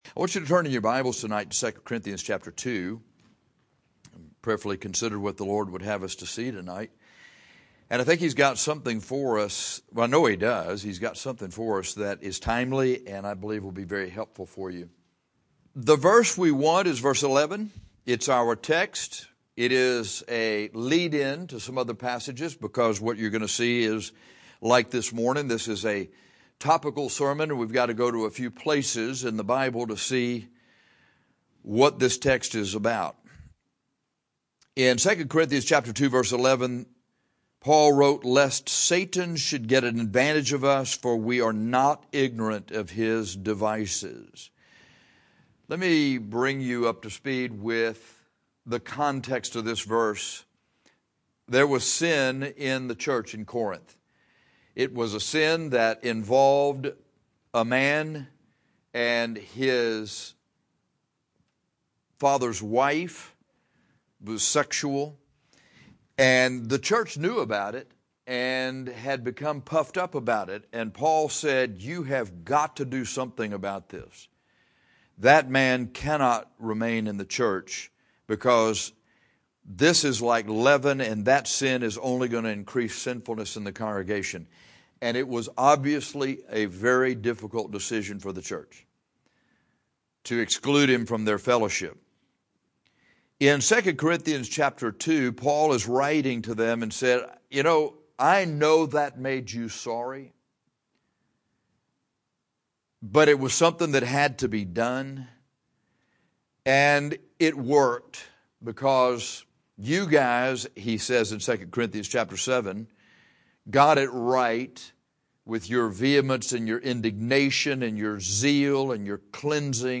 This sermon is to help you not be ignorant of the devices of Satan.